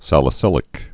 (sălĭ-sĭlĭk)